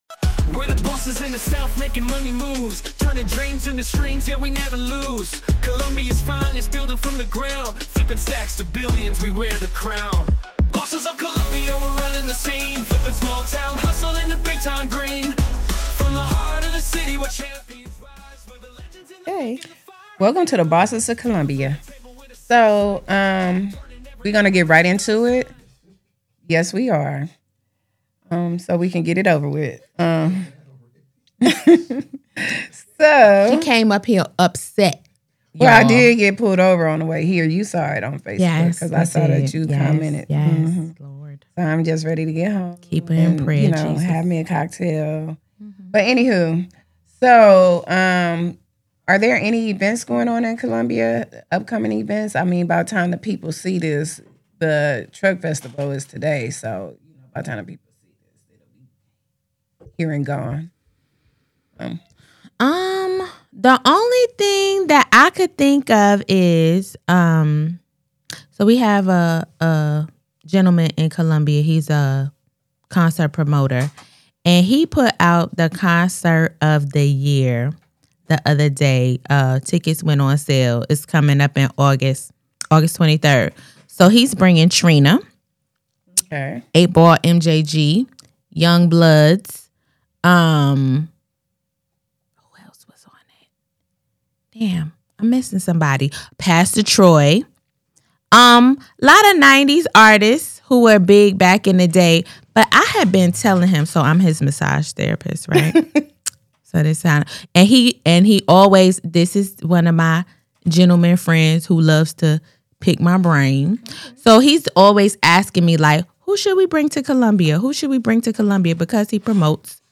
In this deeply candid and thought-provoking episode, the hosts dive into the complexities of modern relationships, marriage, and personal boundaries. From debates on the true meaning of marriage and the freedom it entails, to the emotional and financial realities of divorce, the conversation lays bare the struggles many face when balancing love, independence, and self-worth.